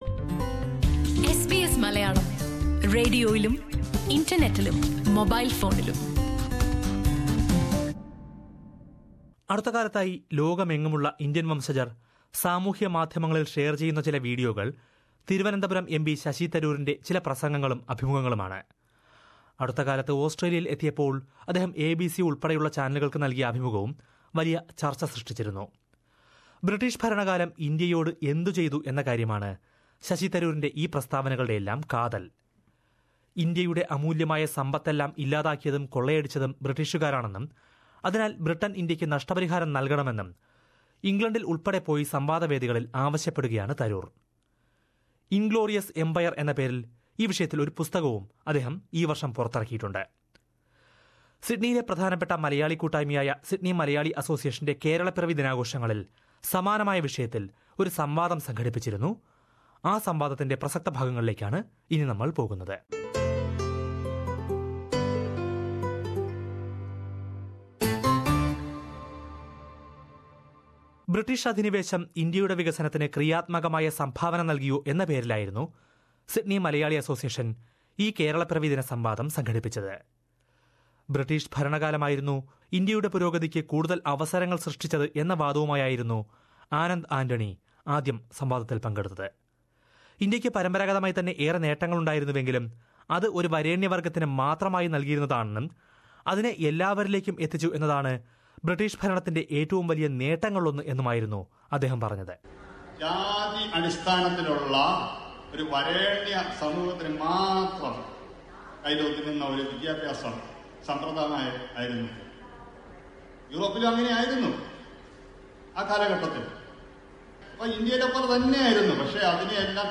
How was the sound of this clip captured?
Sydney Malayalee Association debate on the benefits of British rule to India